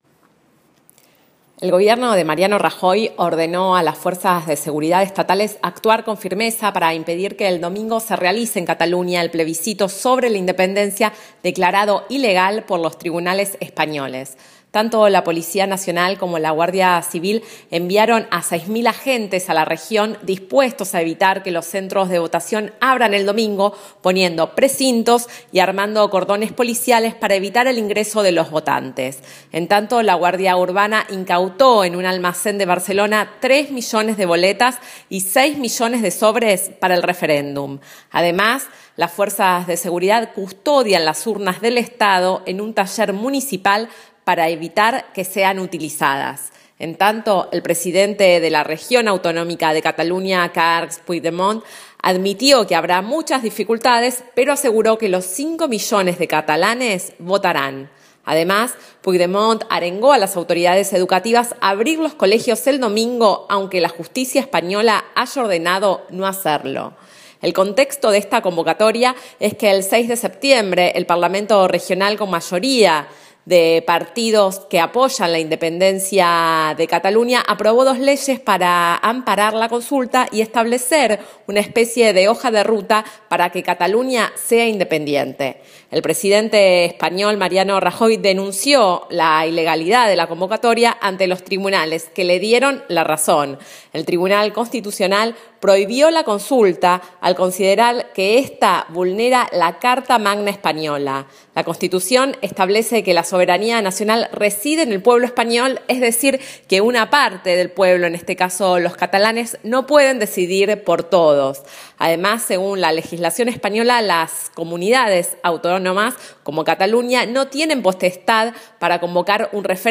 REFERENDUM DE CATALUÑA Informativo
Informe-Cataluna.m4a